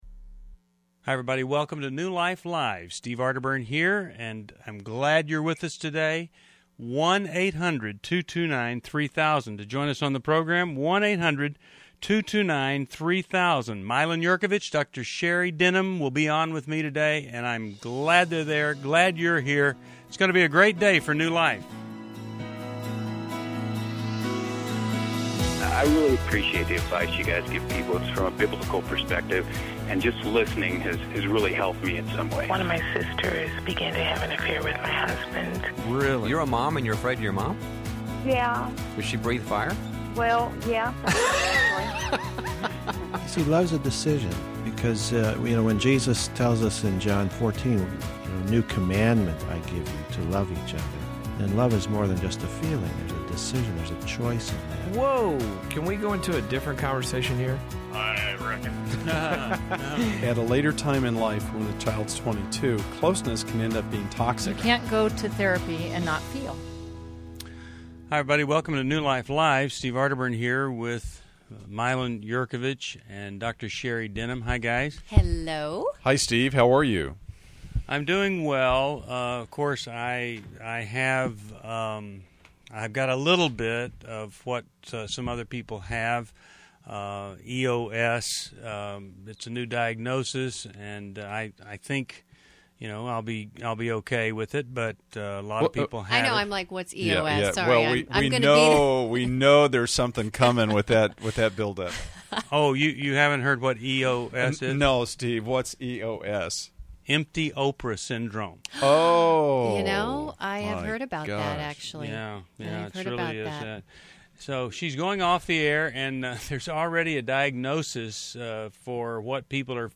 Explore heartfelt insights and support on New Life Live: May 25, 2011, as hosts and callers discuss personal growth and the importance of giving.